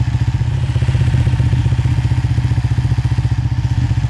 Engine_loop_3.ogg